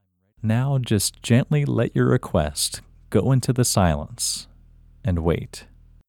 LOCATE OUT English Male 26